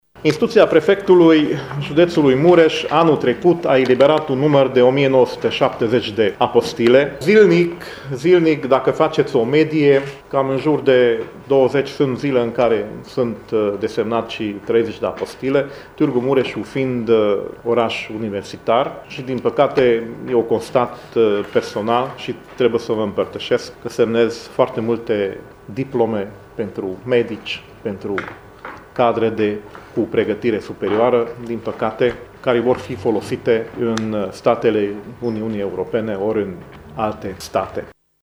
Subprefectul judeţului Mureş, Nagy Zsigmond, a declarat că, zilnic instituţia eliberează 20-30 de apostile şi că multe dintre acestea sunt pentru legalizarea actelor de studii ale medicilor care vor să plece la muncă în străinătate.